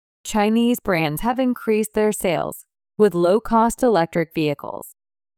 ２）スロー（前半／後半の小休止あり）